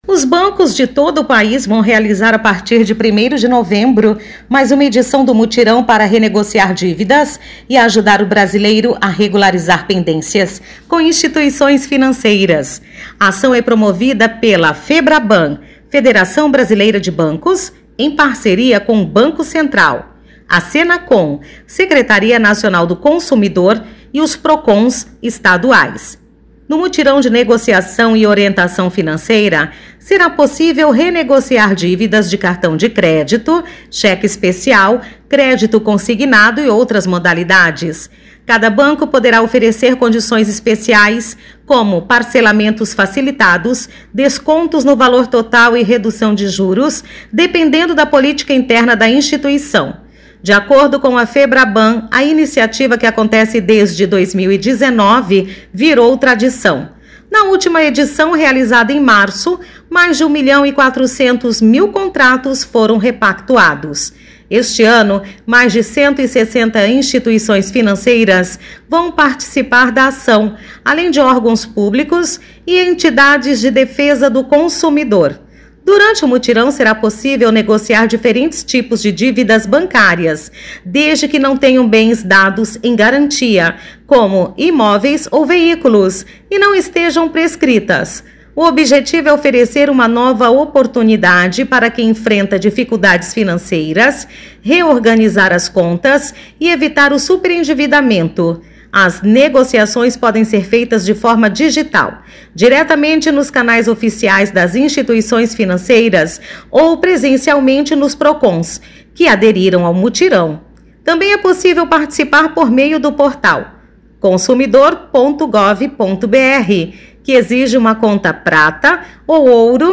Boletins de MT 28 out, 2025